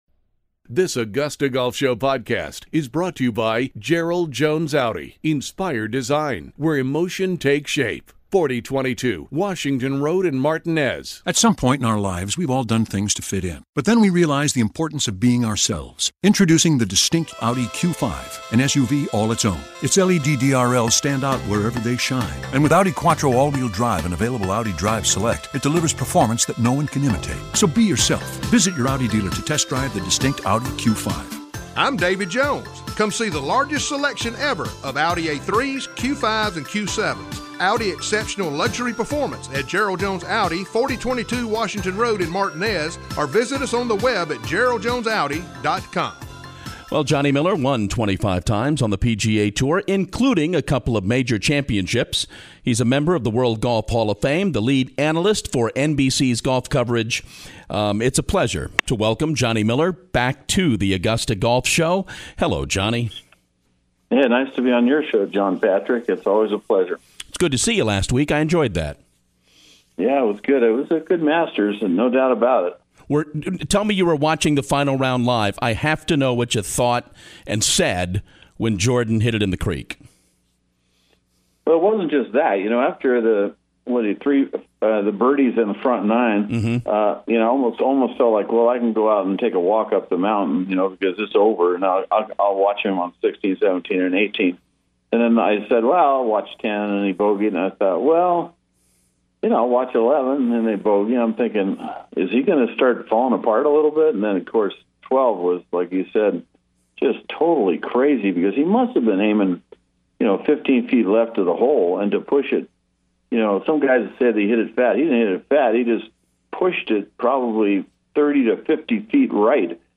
Johnny Miller from NBC Sports is on the show to share his thoughts about the 2016 MASTERS